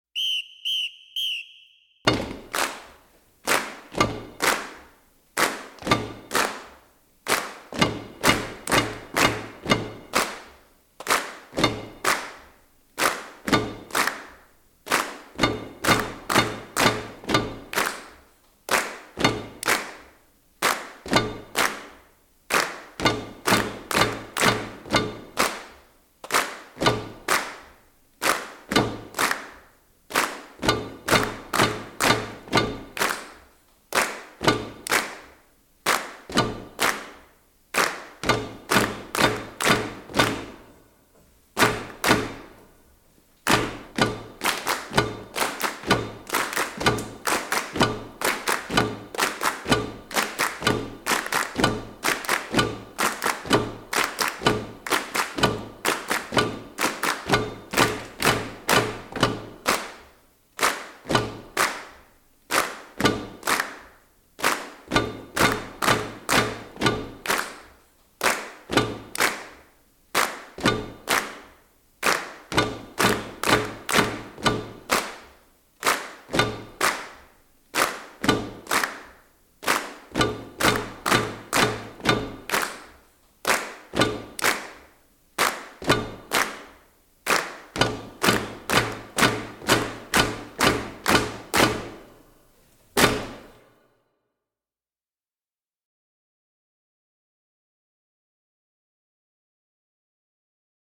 including this track featuring kid percussion only.